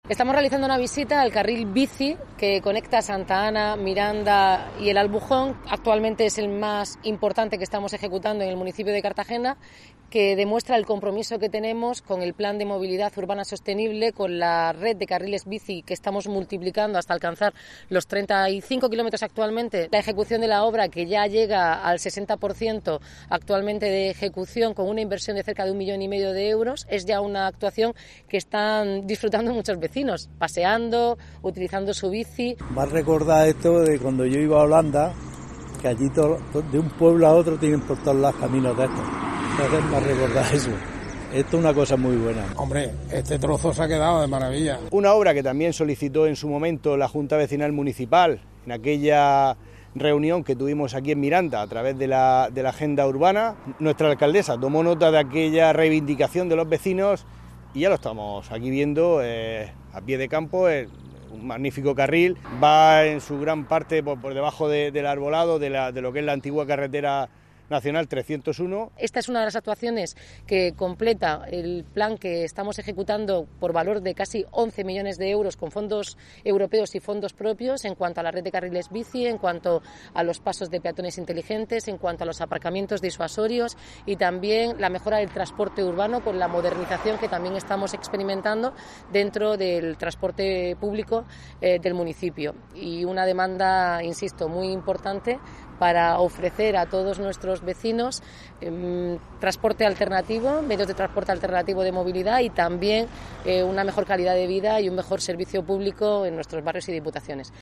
Enlace a Declaraciones de la alcaldesa, Noelia Arroyo; vecinos de la zona y el presidente de la Junta Municipal